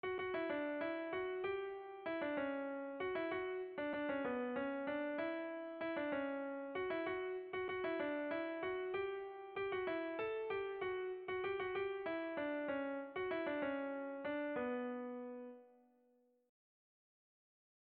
Sentimenduzkoa
Zortziko txikia (hg) / Lau puntuko txikia (ip)
A1A2BA3